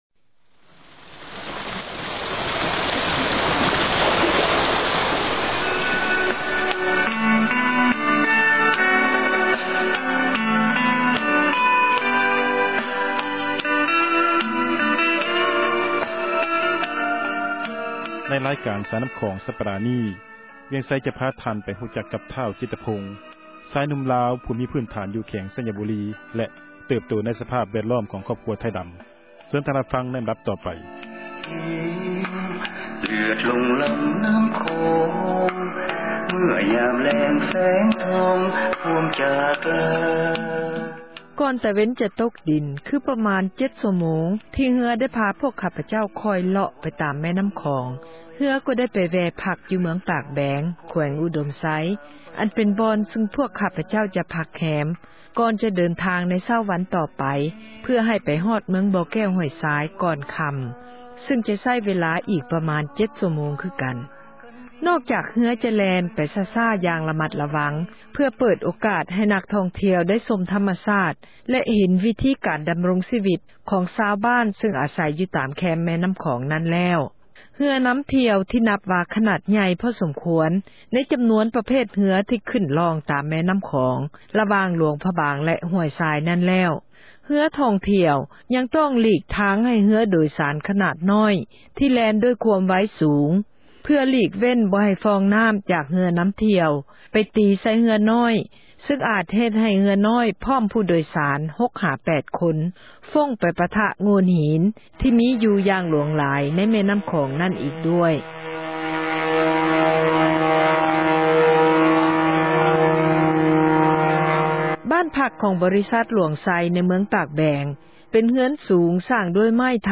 ສາຣະຄະດີ "ສາຍນຳ້ຂອງ" ສຳລັບຕອນນີ້